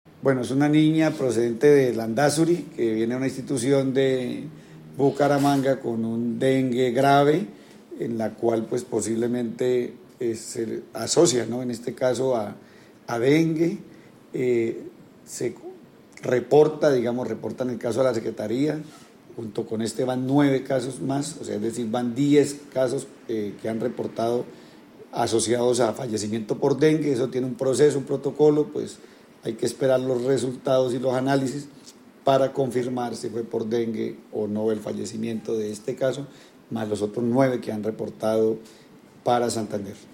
Edwin Prada, secretario de Salud de Santander